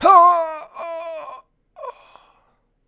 death0.wav